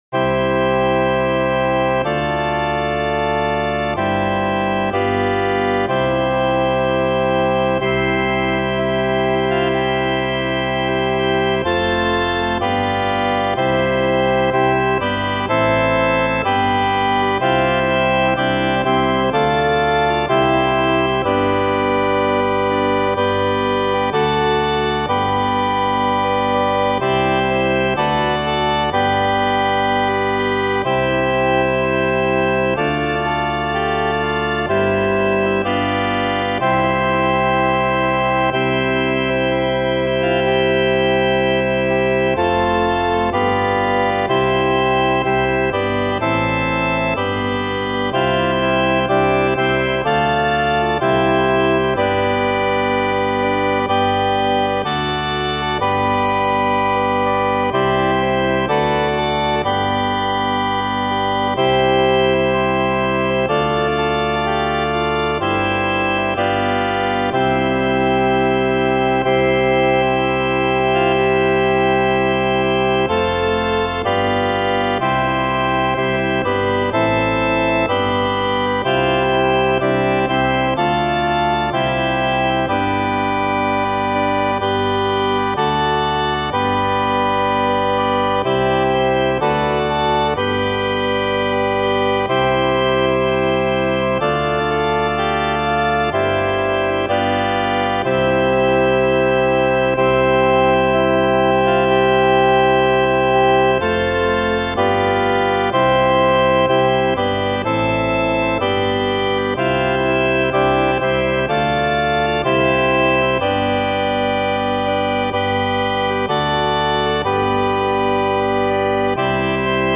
Here is a fake organ backing from BIAB to sing along to.